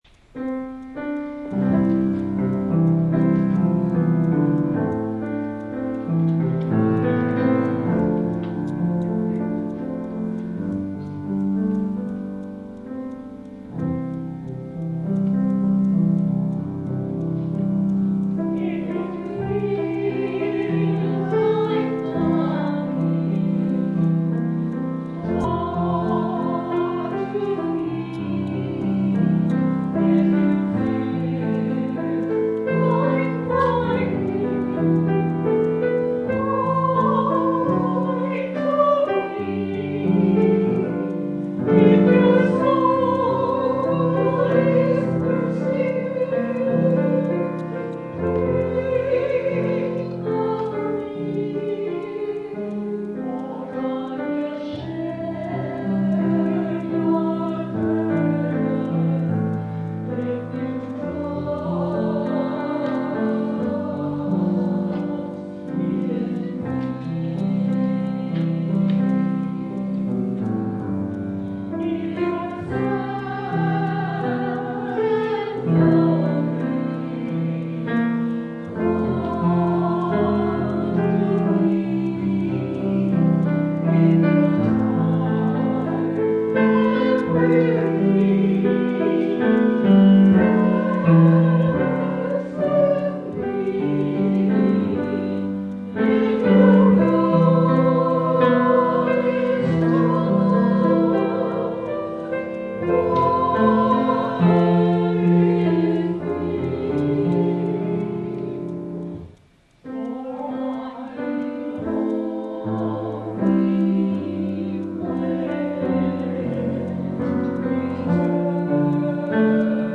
Download   Music / Anthem: “Trust in Me” – Dunbar – 9/25/2016